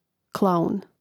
Rastavljanje na slogove: kla-un